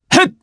Ezekiel-Vox_Jump_jp.wav